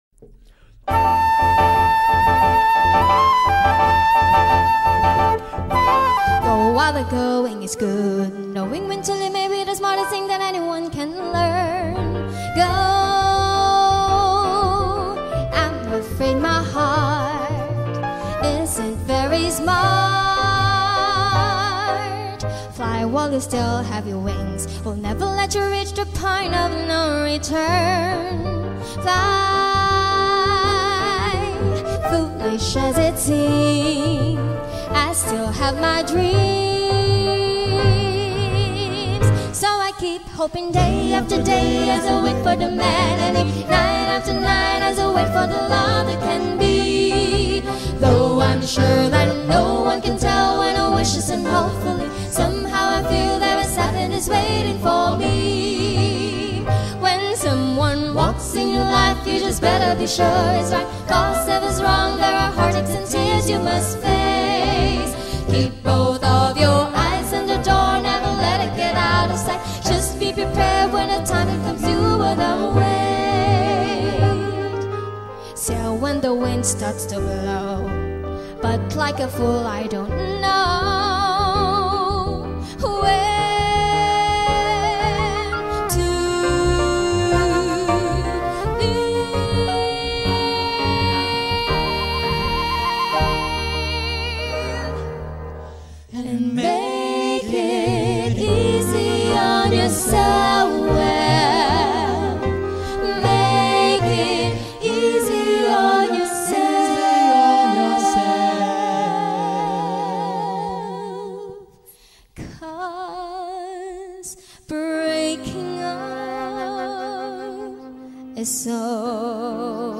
Jazz Harmony band